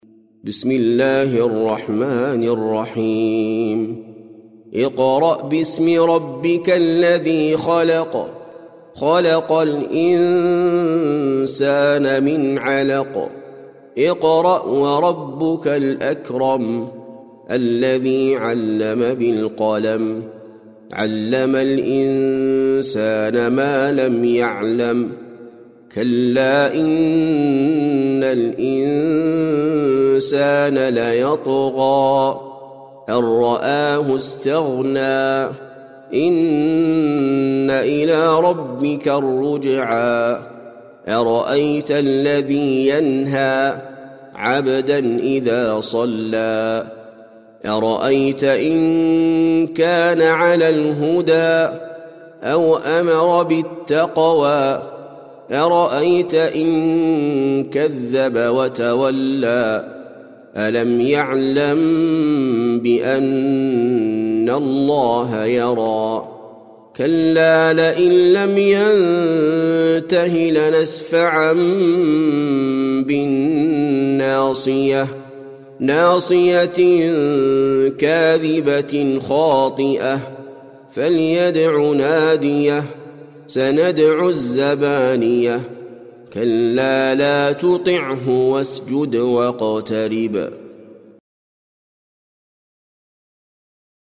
سورة العلق - المصحف المرتل (برواية حفص عن عاصم)
جودة عالية